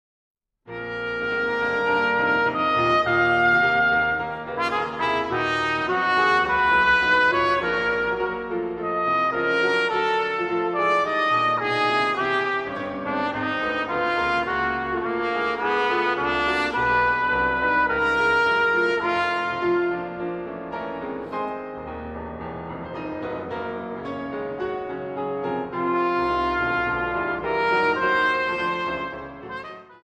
Piano
F?r Trompete in B und Klavier